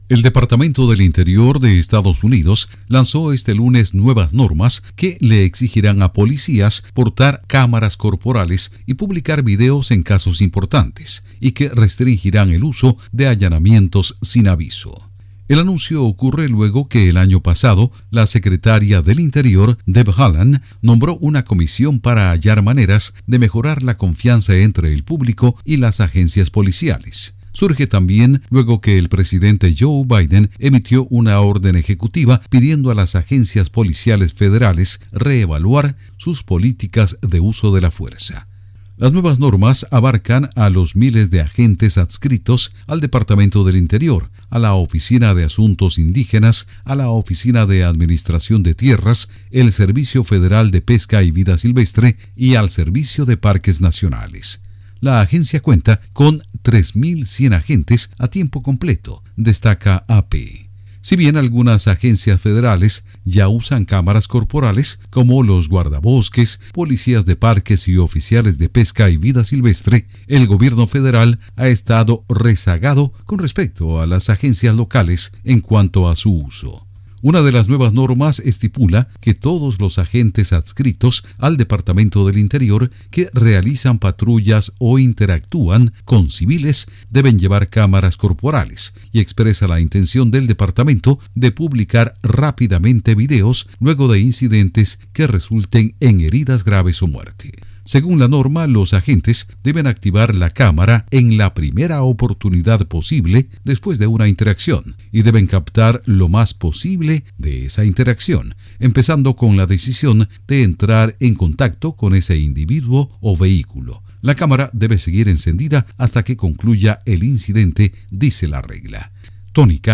EEUU exigirá a policías federales llevar cámaras corporales. Informa desde la Voz de América en Washington